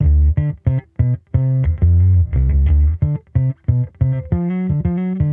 Index of /musicradar/sampled-funk-soul-samples/90bpm/Bass
SSF_PBassProc2_90D.wav